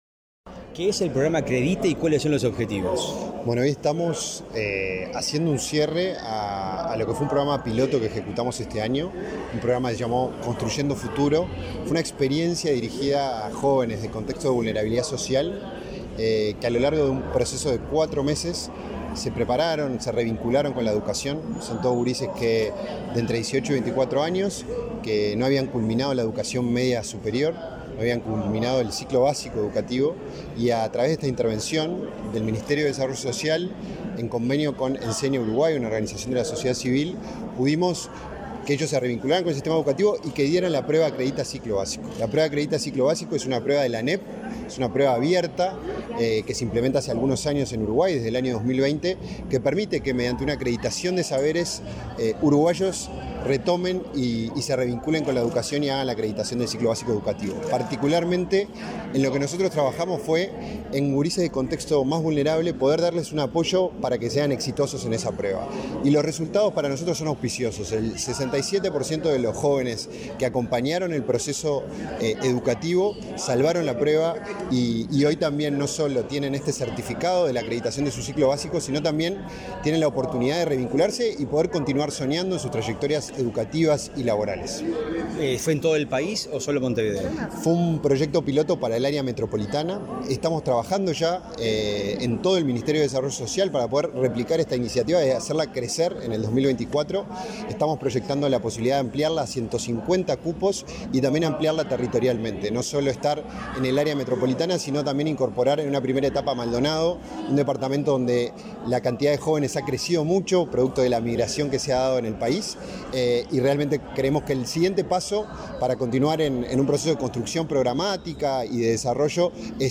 Entrevista al director del Inju, Felipe Paullier